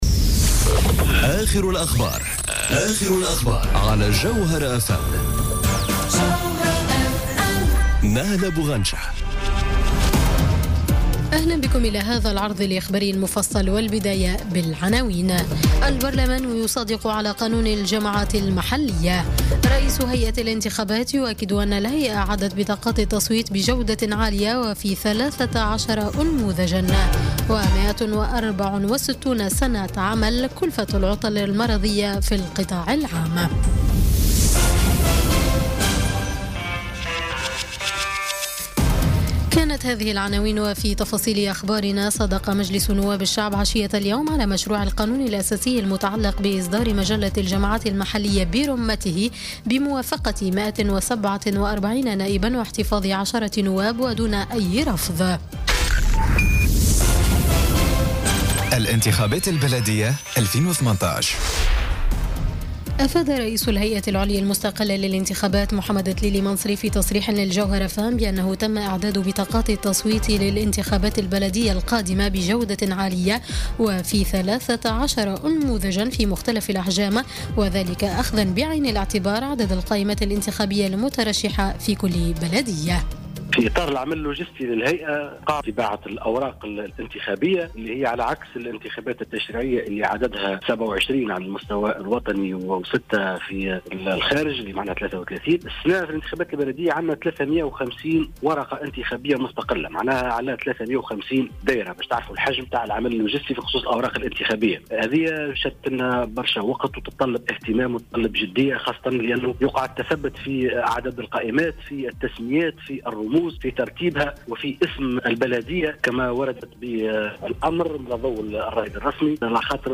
نشرة أخبار السابعة مساءً ليوم الخميس 26 أفريل 2018